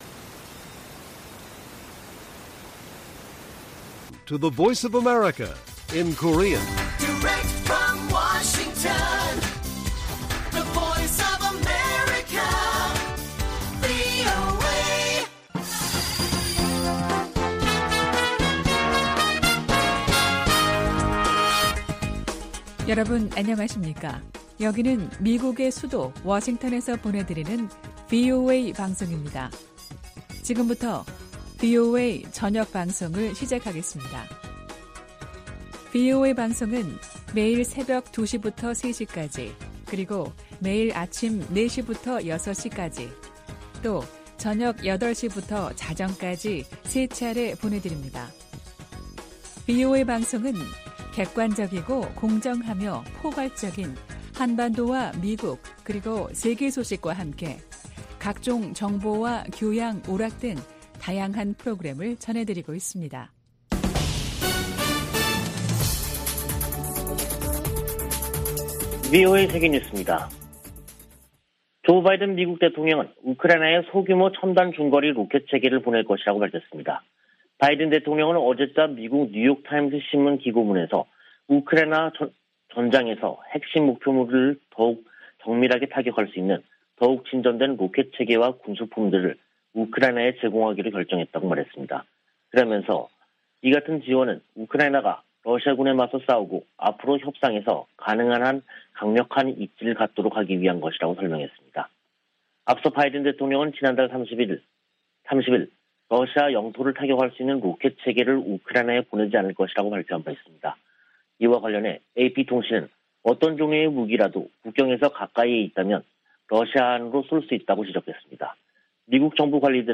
VOA 한국어 간판 뉴스 프로그램 '뉴스 투데이', 2022년 6월 1일 1부 방송입니다. 미국은 북한이 핵 실험을 실시한다면 유엔 안보리에서 추가 제재를 추진할 것이라고 유엔주재 미국 대사가 밝혔습니다. IPEF가 미국과 인도 태평양 지역 국가들 간 경제 관계를 더욱 강화할 것이라고 미 상무장관이 말했습니다. 미 중앙정보국(CIA)은 최근 갱신한 ‘국가별 현황보고서’에서 북한의 올해 ICBM 시험을 주목하고, 극심한 식량부족이 우려된다고 지적했습니다.